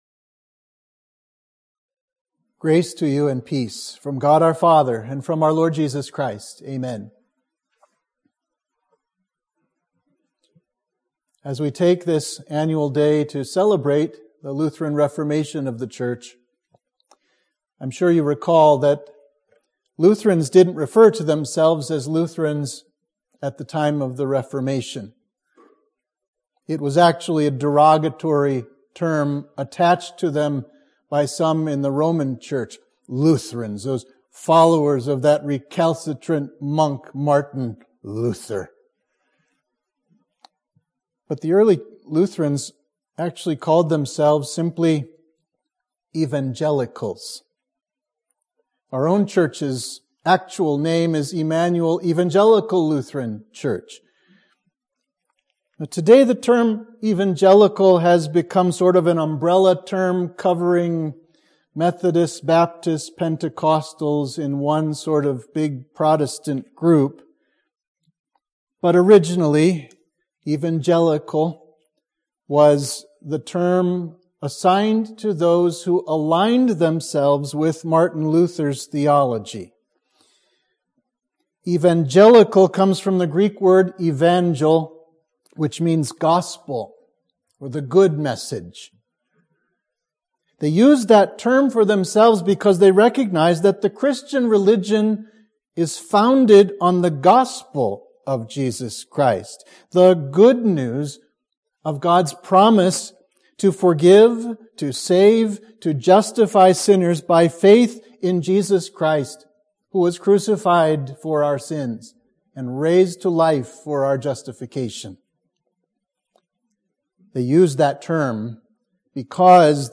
Sermon for the Festival of the Reformation